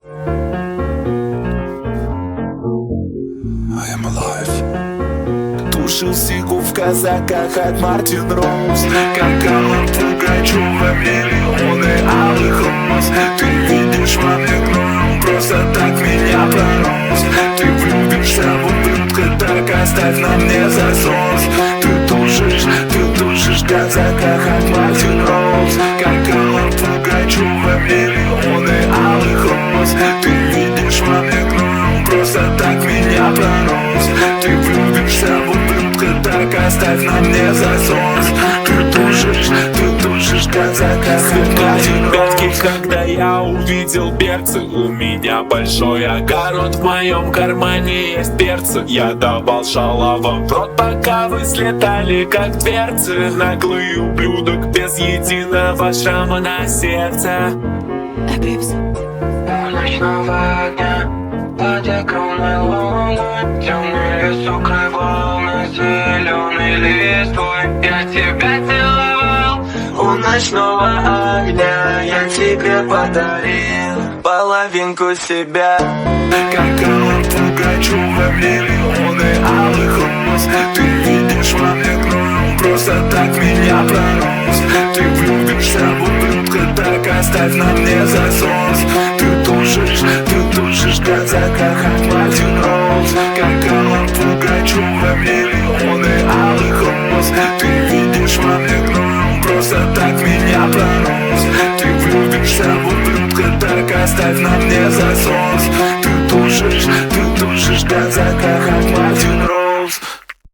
Качество: 320 kbps, stereo
Рэп